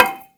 Perc 3 [ ding ].wav